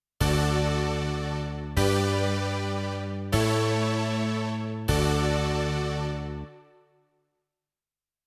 ♪ニ長調の和音進行(mp3)